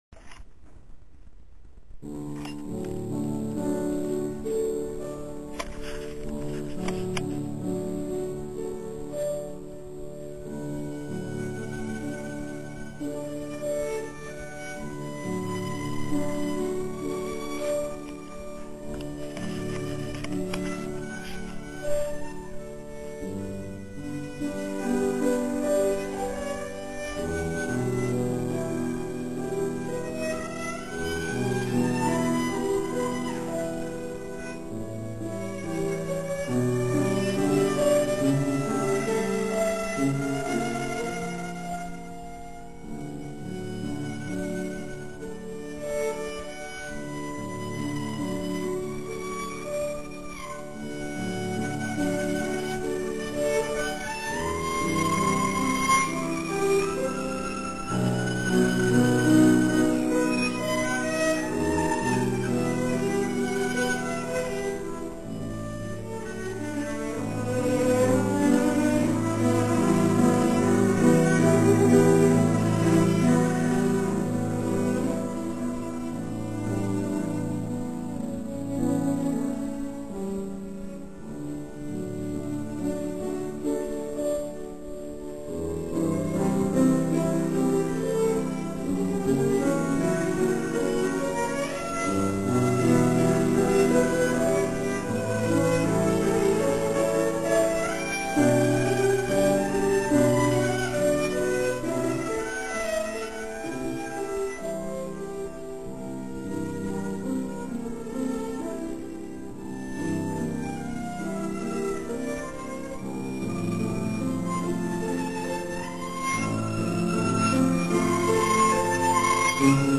19. komorní koncert na radnici v Modřicích
housle
klavírní doprovod
- ukázkové amatérské nahrávky, v ročence CD Modřice 2006 doplněno: